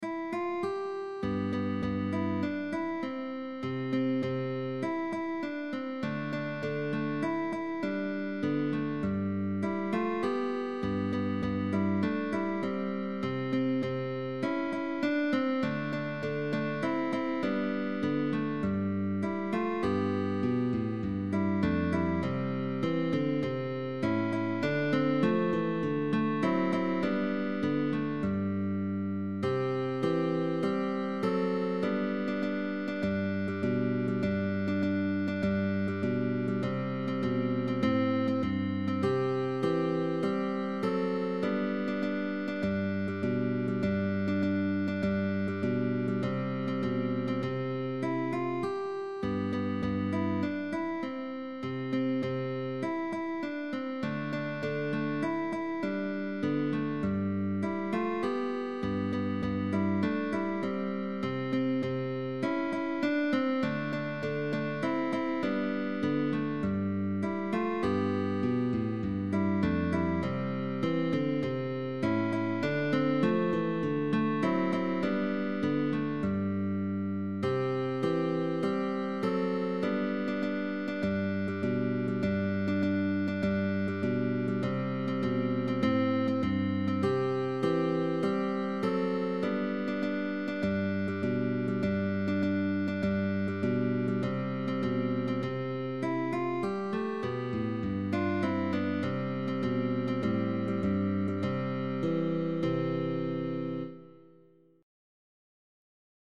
GUITAR TRIO